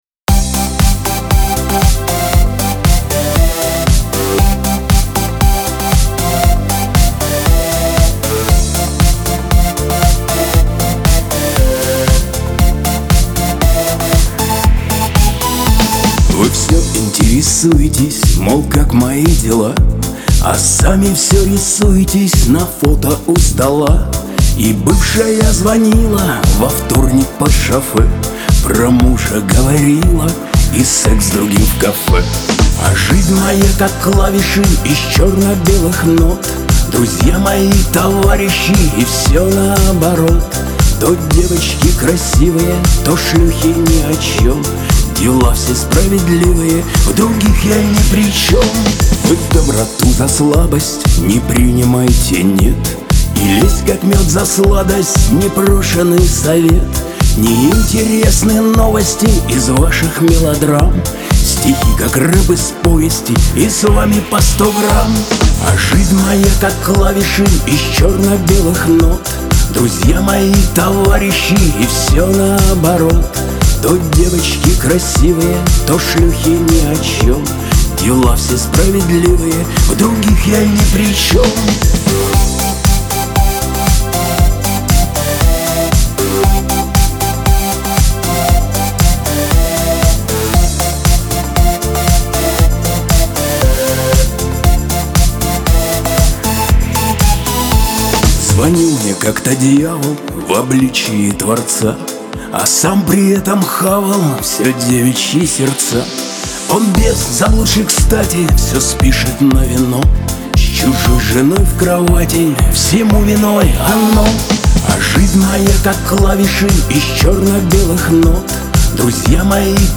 Шансон